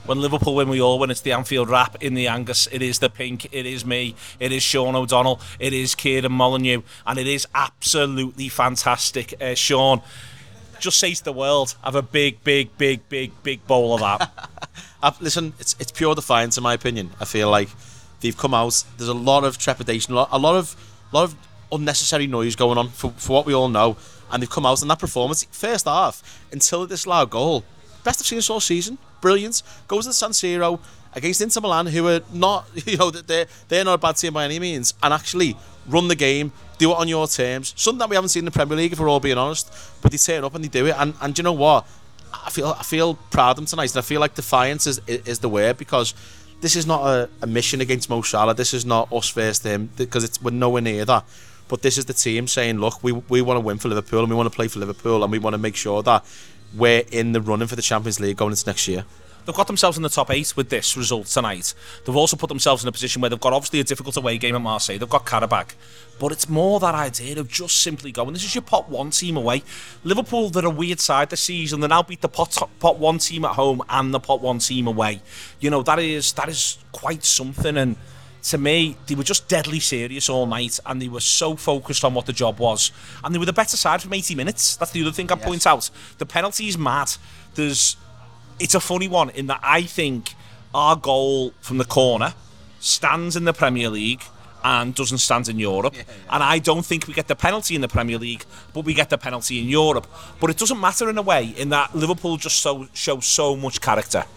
Below is a clip from the show – subscribe for more Inter 0 Liverpool 1 reaction…